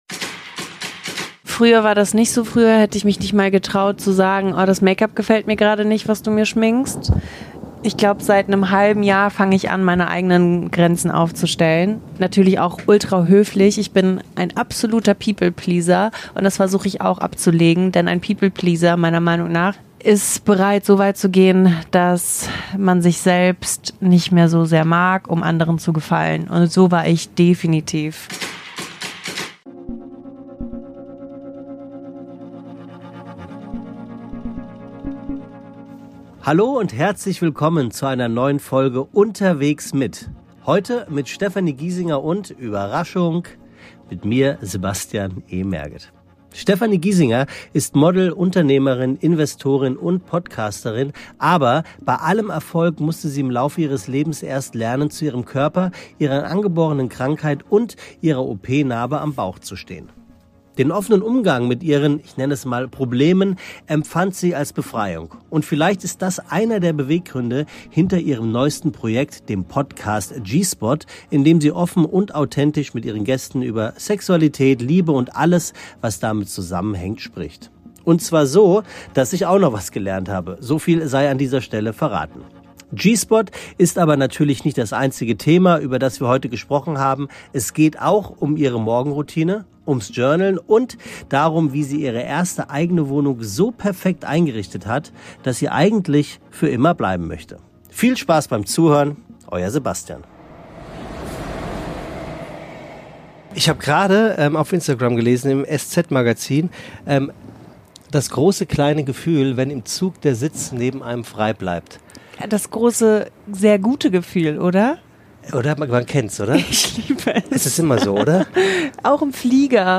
Ein emotionales und amüsantes Gespräch über die Vorteile des Alleinreisens, Journaling und darüber, was Stefanies Inneneinrichtung mit Aliens zu tun hat.